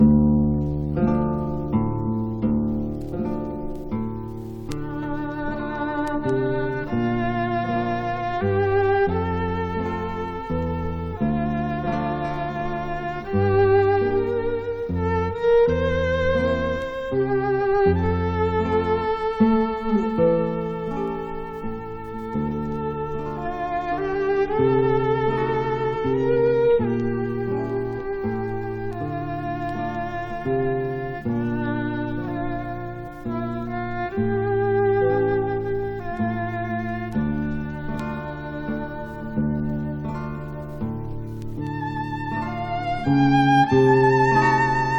片面ずつに「クラシック」「モダン」と銘打って、ギターとヴィオラとフルートという編成で。
Classical　USA　12inchレコード　33rpm　Mono